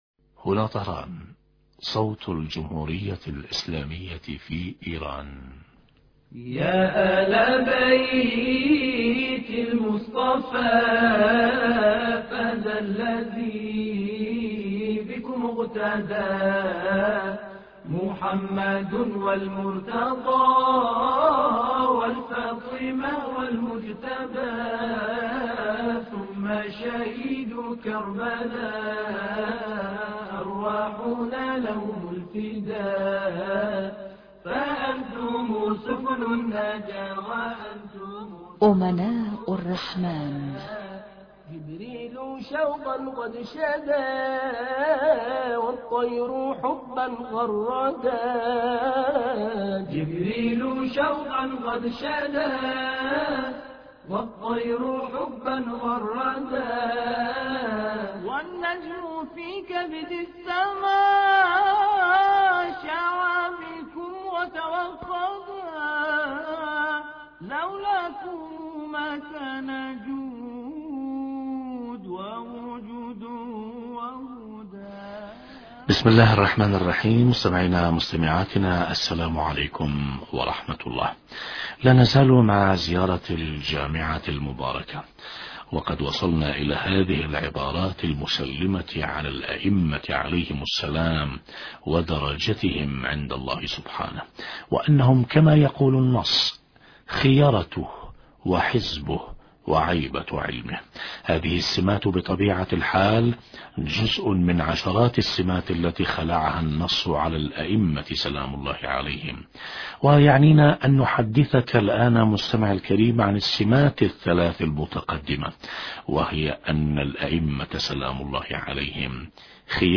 شرح فقرة (عيبة علم الله) حديث عن حفظ الائمة(ع) للعلم الالهي وترويجه مقابلة